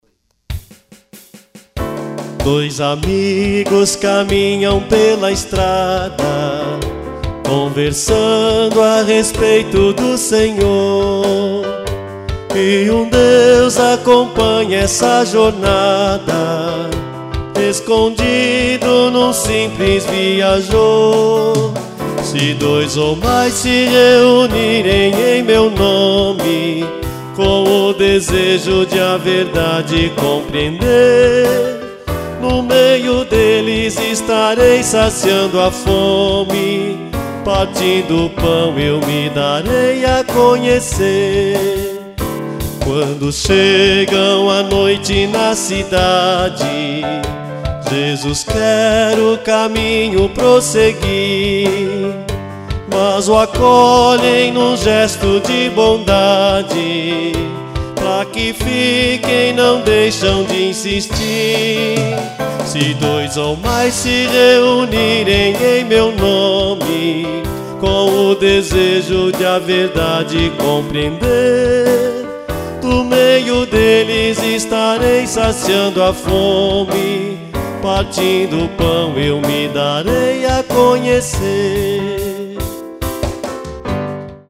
Canto de Igreja.